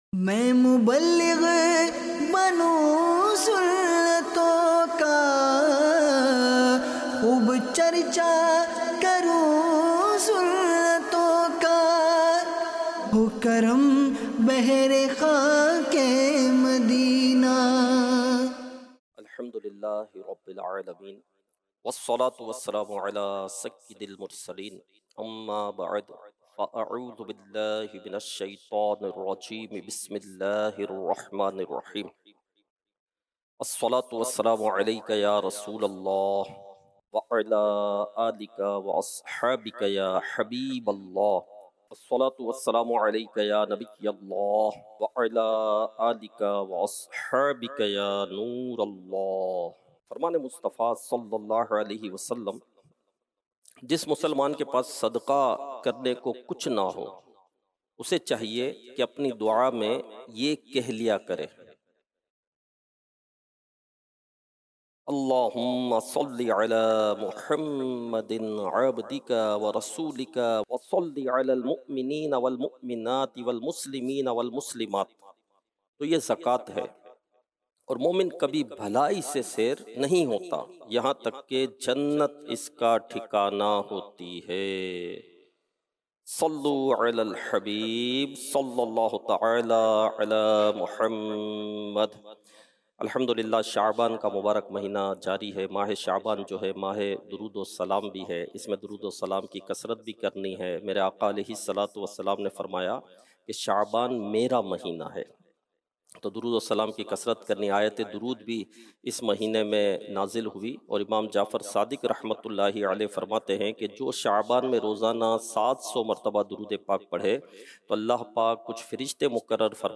Sunnaton Bhara Bayan - Gunahon Ki Nahusat